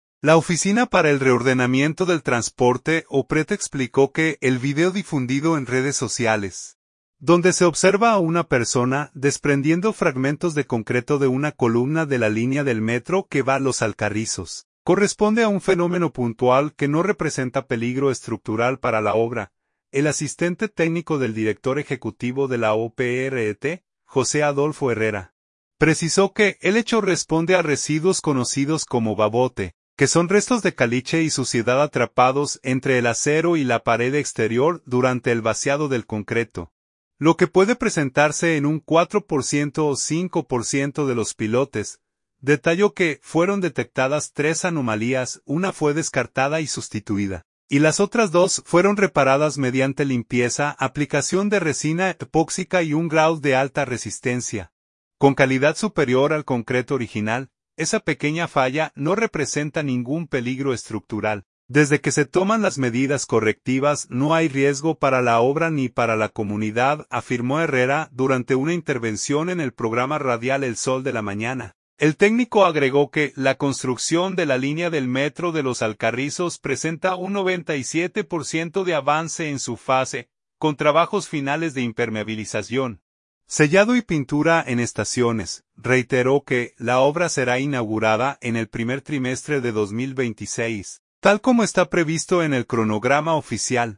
intervención en el programa radial El Sol de la Mañana